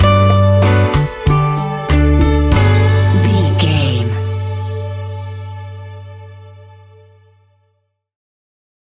An exotic and colorful piece of Espanic and Latin music.
Ionian/Major
Slow
maracas
percussion spanish guitar